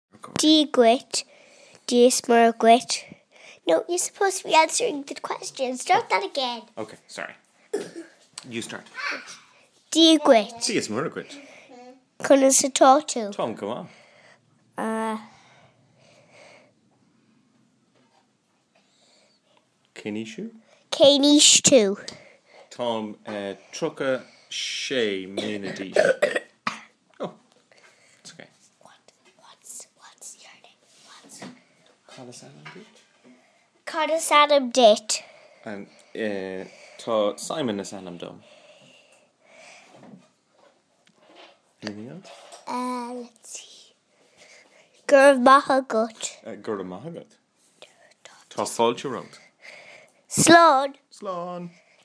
Patrick's Day Irish Interview